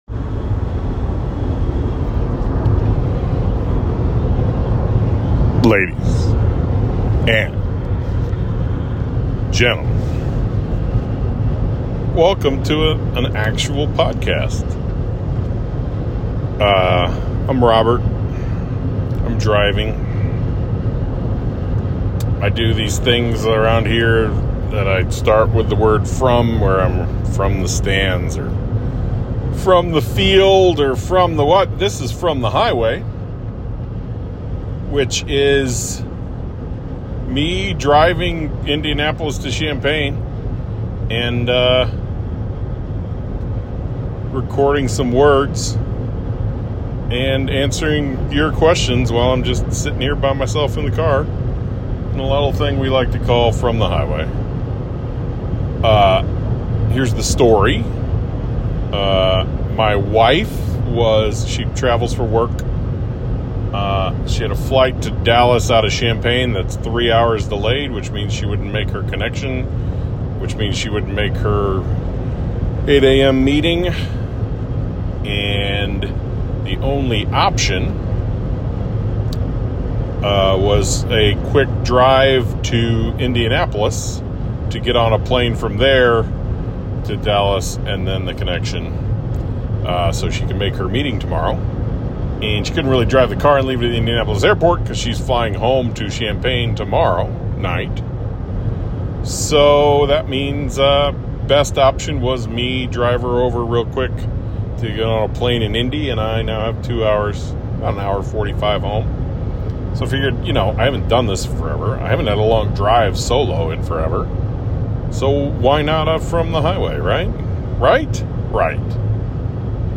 So with a two-hour solo drive back to Champaign ahead of me, I asked for questions on Twitter and Slack and then answered those questions from I-74. I restricted the questions to four categories: Illini football, Illini basketball, Illini golf, and my high school tennis career.